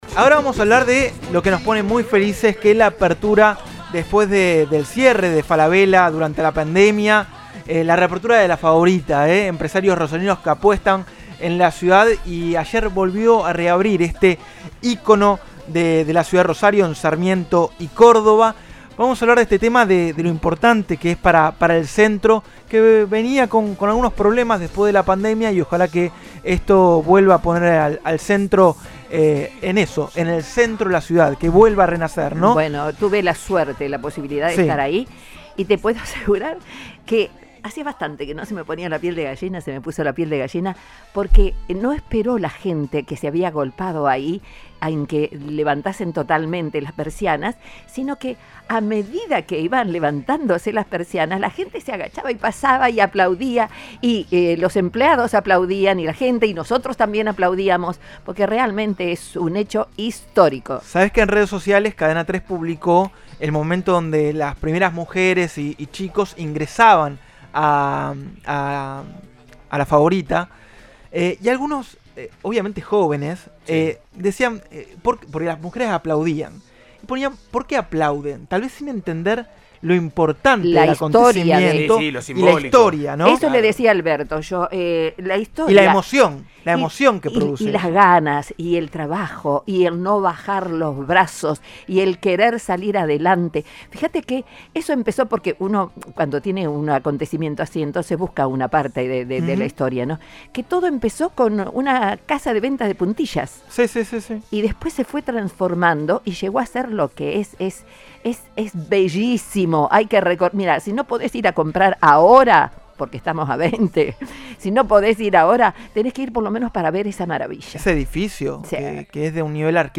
El secretario de Desarrollo Económico y Empleo de la Municipalidad habló en Cadena 3 sobre icónica revitalización de la esquina de Sarmiento y Córdoba, su impacto en todo el centro y la ciudad.